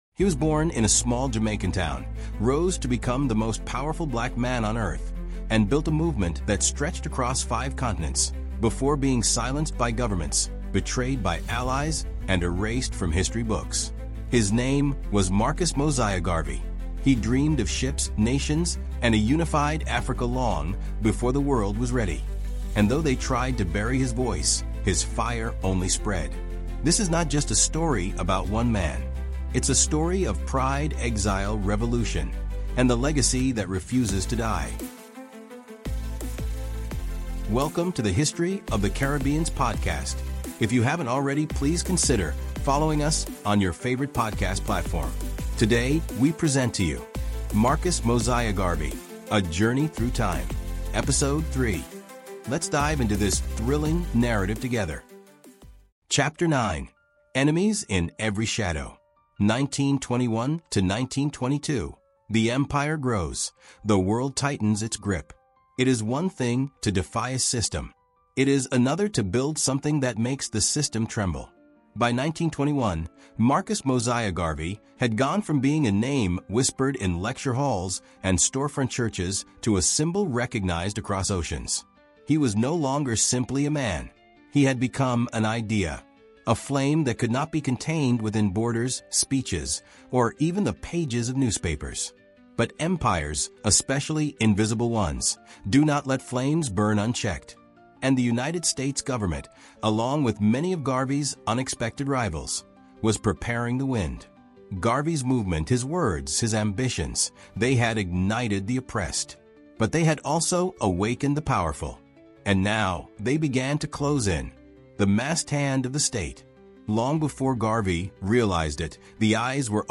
From his humble beginnings in St. Ann’s Bay, Jamaica, to becoming the architect of the world’s largest Black movement, this 16-chapter historical epic takes you deep into Garvey’s bold dreams, his rise to international prominence, his persecution by world powers, and the resurrection of his legacy through revolution, rhythm, and remembrance. Through rich storytelling and immersive narration, discover how Garvey inspired generations—from African presidents and civil rights leaders to Rastafarians, poets, and musicians across the globe.